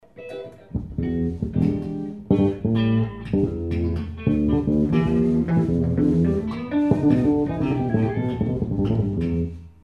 Below are some short mp3's of the new bass.
The settings on the amp are: EQ, Enhance, Bass, Treble, Crossover, Balance, Shelving knobs all at 12'o-clock (Flat).
The settings on the bass are: both pickups on full, bass all the way up, treble and mid eq's at center detent.